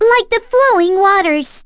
Lilia-Vox_Victory.wav